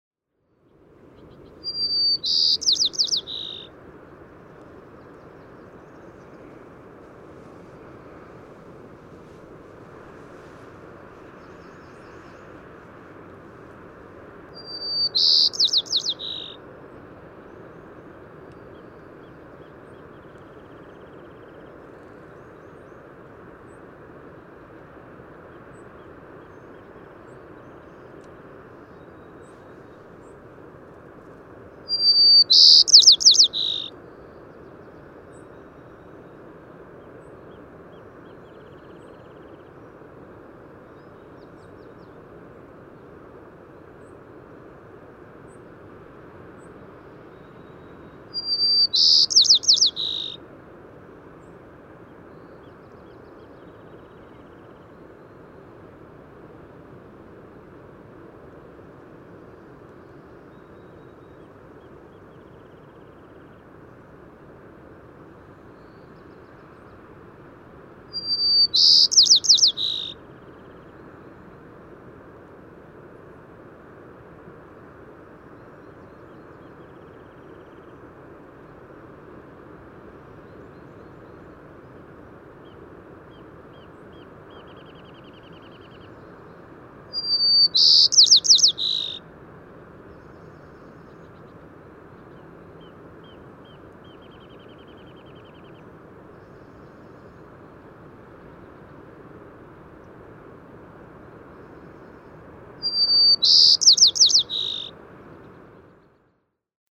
White-crowned sparrow
245_White-crowned_Sparrow.mp3